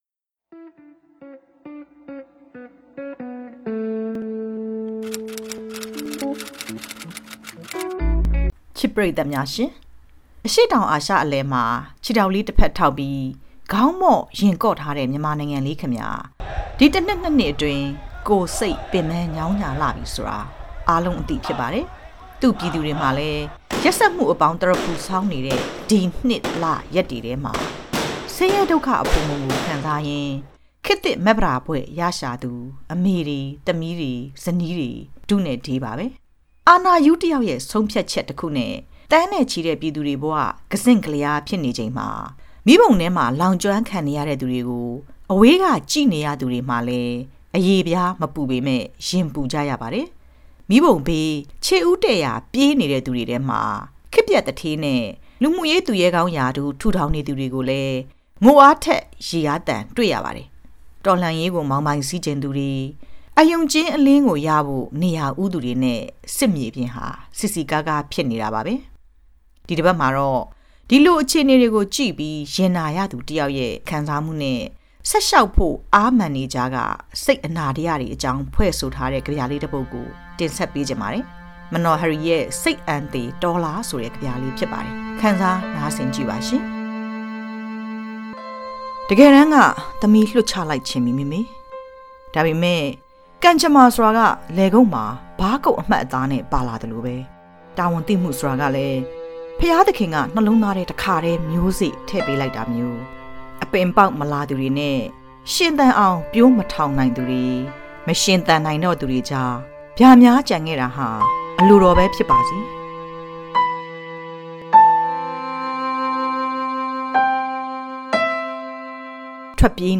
ဒီတစ်ပတ်မှာတော့ တစ်စတစ်စရှည်ကြာလာတဲ့ တော်လှန်ရေးကာလထဲက ပြည်သူလူထုရဲ့ မတူညီတဲ့ အိပ်ပျက်ညတွေအကြောင်း ဖွဲ့ဆိုထားတဲ့ ကဗျာလေးတစ်ပုဒ်ကို တင်ဆက်ထားပါတယ်။